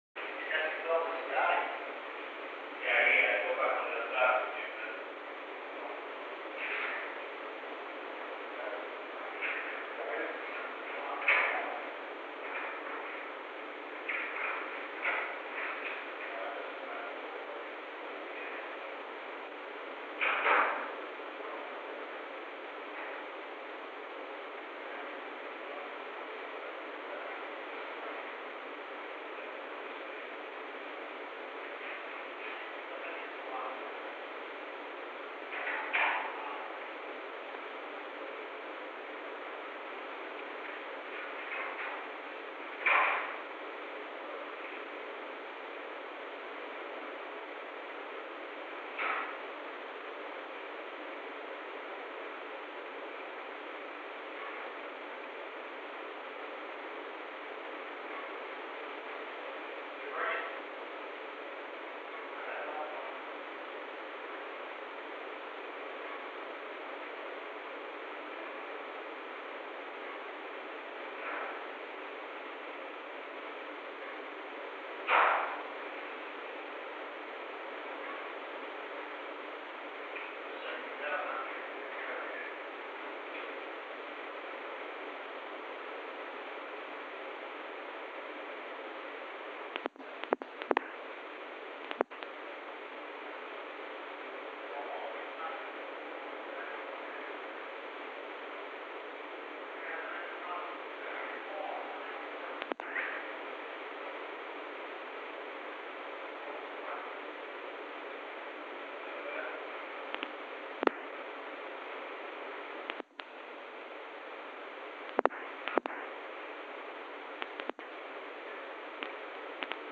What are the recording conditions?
Recording Device: Oval Office The Oval Office taping system captured this recording, which is known as Conversation 863-004 of the White House Tapes.